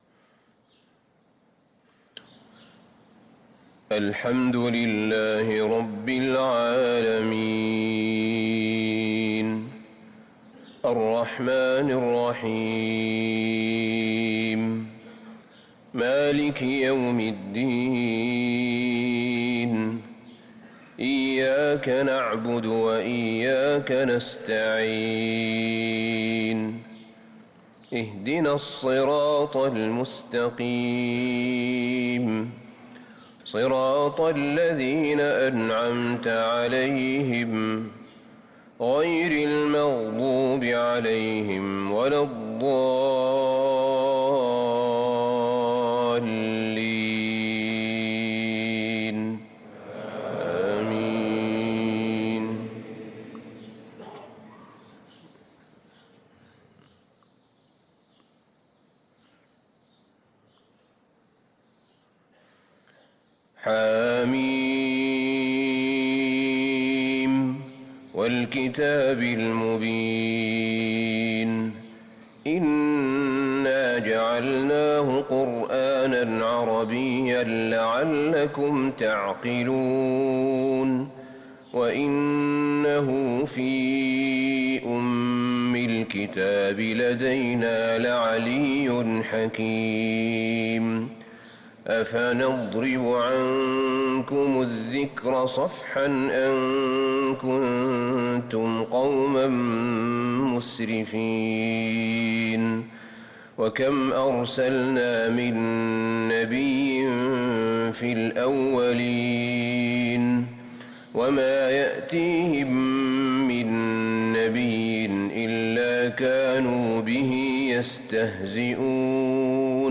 صلاة الفجر للقارئ أحمد بن طالب حميد 20 ربيع الأول 1445 هـ